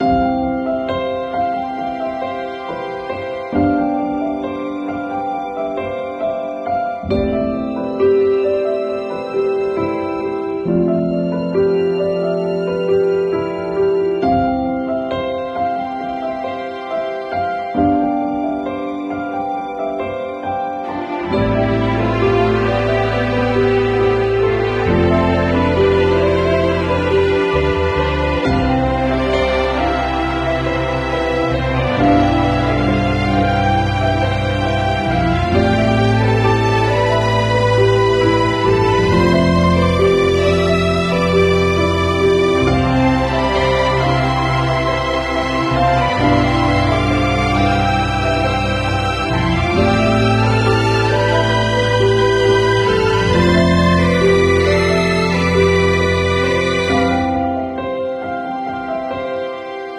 soul-stirring melodies